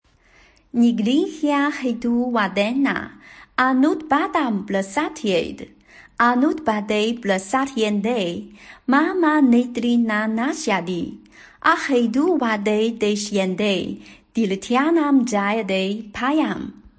楞伽经3.91朗读.mp3.ogg